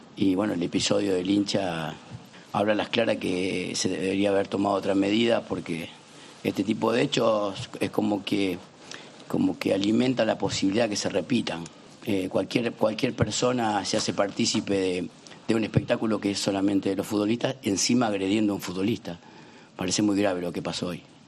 El técnico del Sevilla protestó en rueda de prensa la decisión del árbitro de continuar el partido tras la agresión del espontáneo a Dmitrovic.